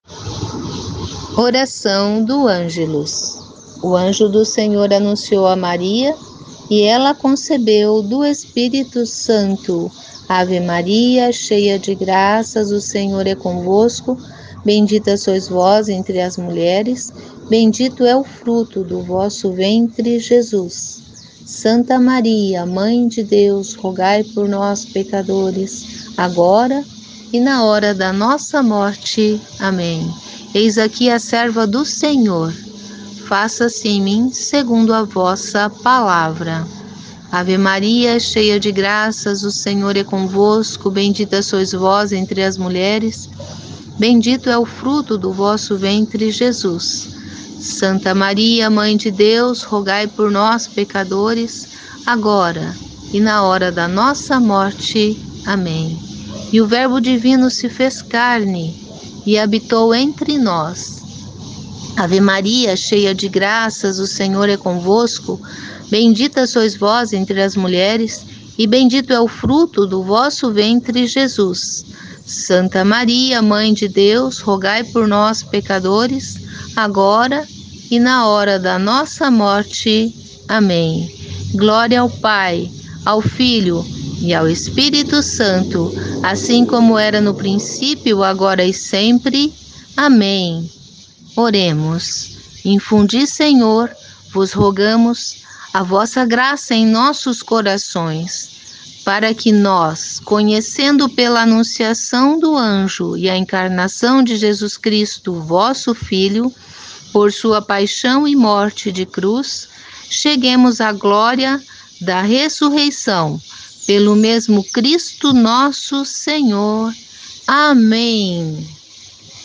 Ouça a oração do Angelus